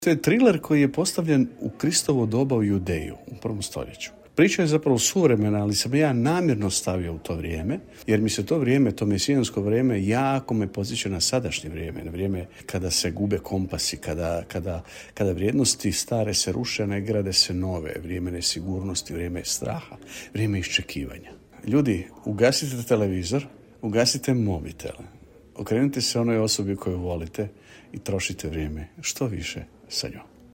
U Gradskoj knjižnici Đurđevac održan je književni susret i promocija knjige